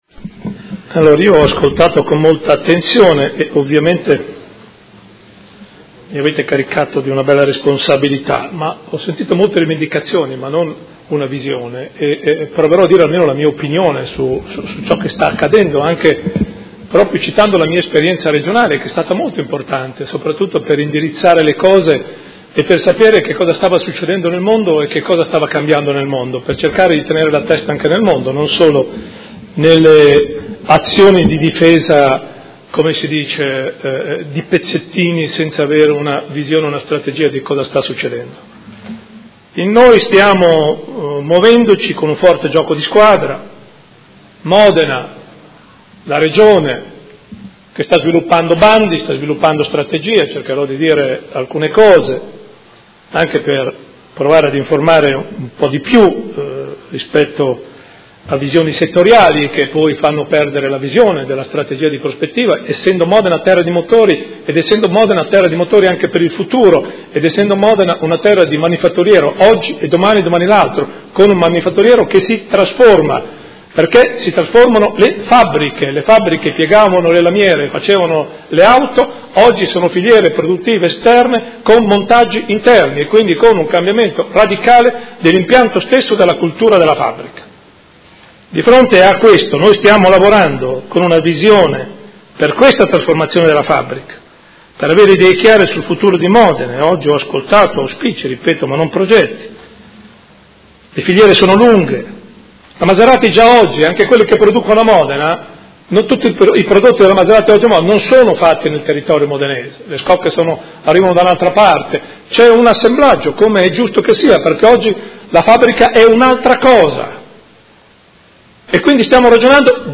Seduta del 20/04/2016. Conclude dibattito su interrogazione dei Consiglieri Malferrari, Trande e Bortolamasi (P.D.) avente per oggetto: Si aggrava la crisi alla Maserati di Modena
Sindaco